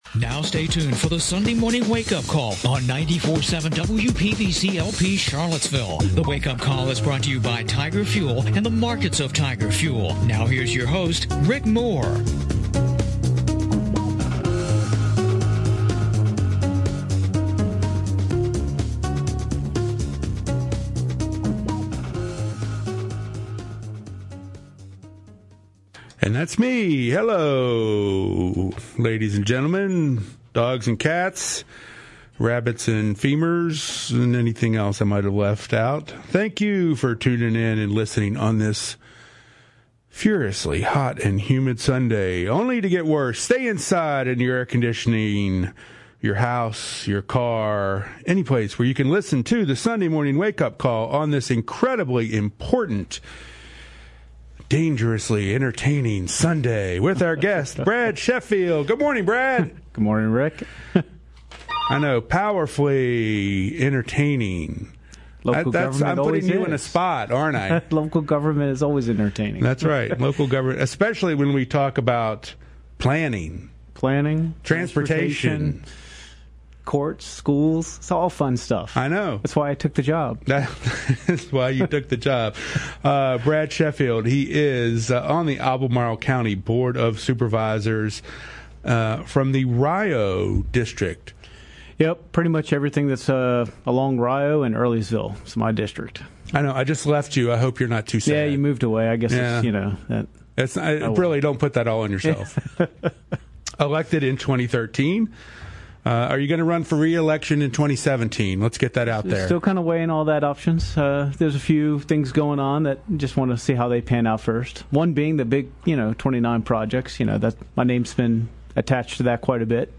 talks to Albermarle County Supervisor Brad Sheffield. Topics include the grade separated intersection at Rio Road and US 29 and it’s effect on the decision to grade separate at Hydraulic Road.
The Sunday Morning Wake-Up Call is heard on WPVC 94.7 Sunday mornings at 11:00 AM.